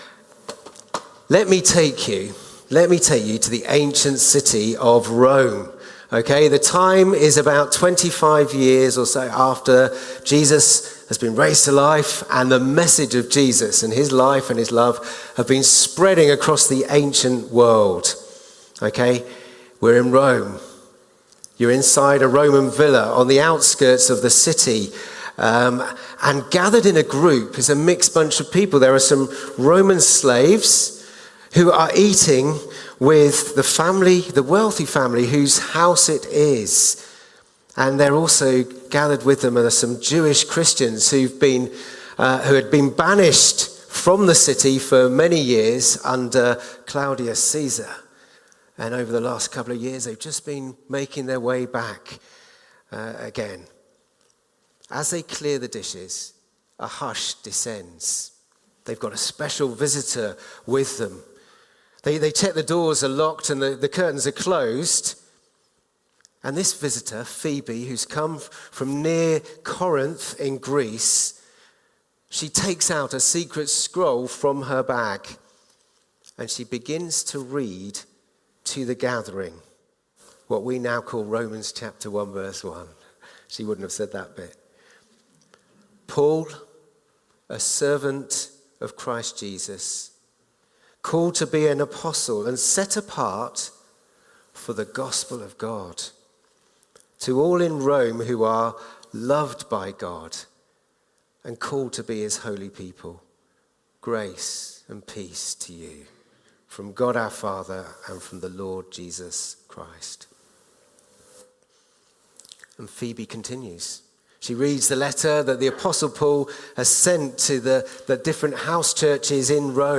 Romans | Sermon Series | Christchurch Baptist, Welwyn Garden City